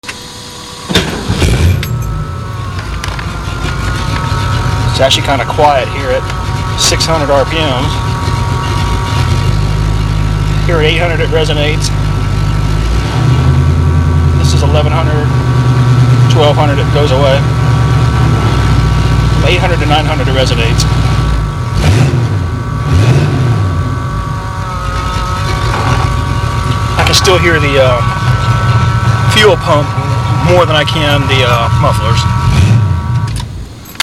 Sound clips of Super 44s mounted temporarily just after the header collector, no other pipe.